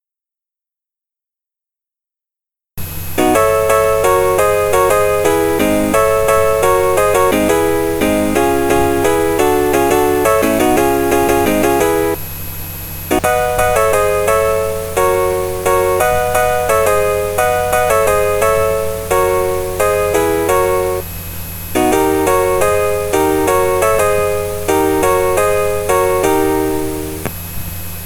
sigma delta dac piano auf arduino